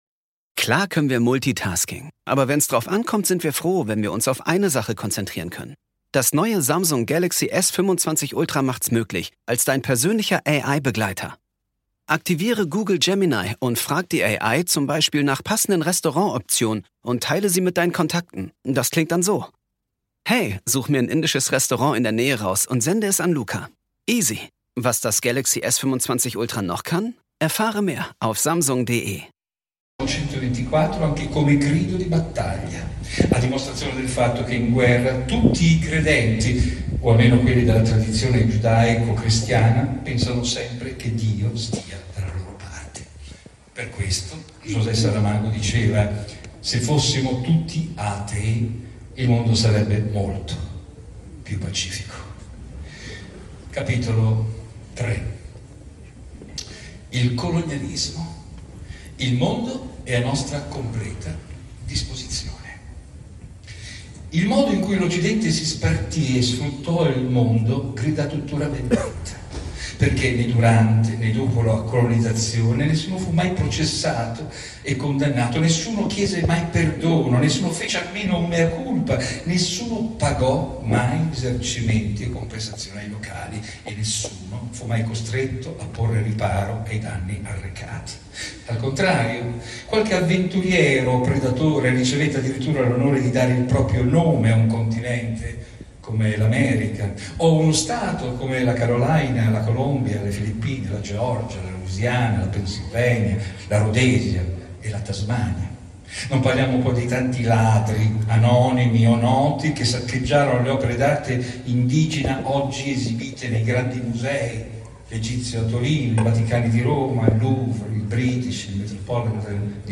Il podcast di Piergiorgio Odifreddi: Lezioni e Conferenze.